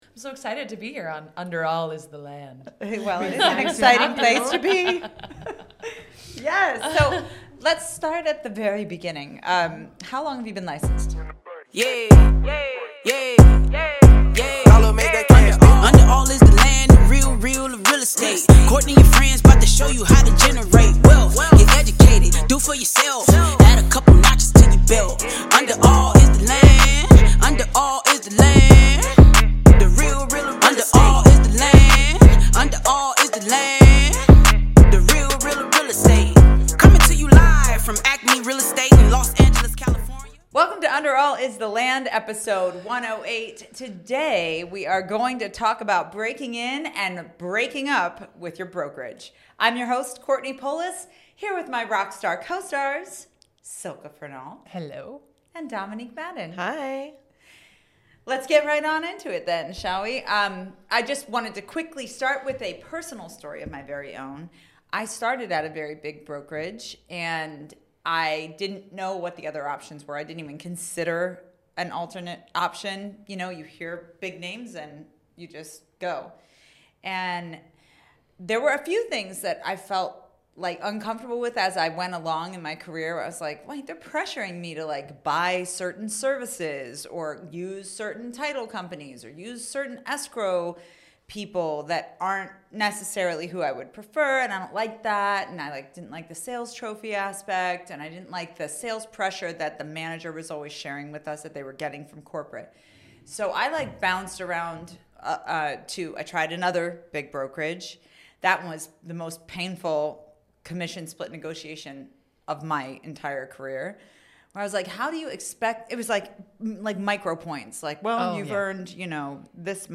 Under All is The Land podcast hosts meet this week to discuss how to interview at a brokerage, questions that send a red flag to the brokers, how to rise within a brokerage, and how to peacefully make brokerage changes.